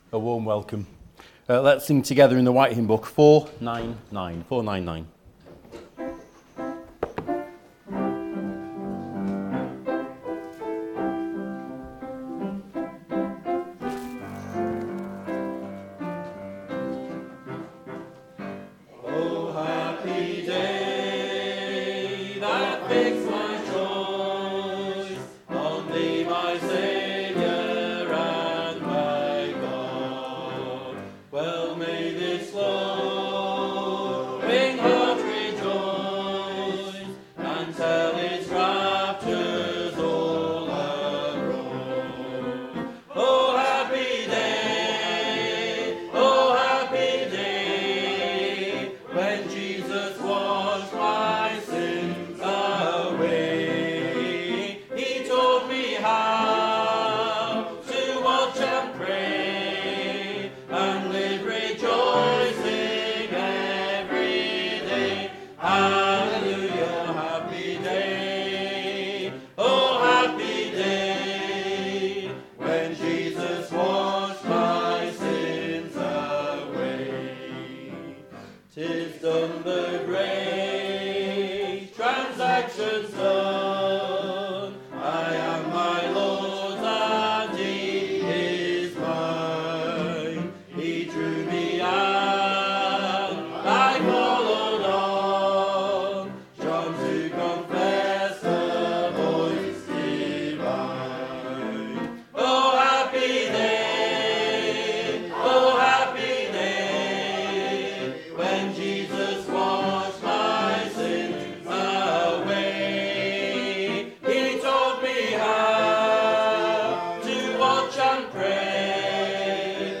Below is audio of the full service.
2026-02-15 Evening Worship If you listen to the whole service on here (as opposed to just the sermon), would you let us know?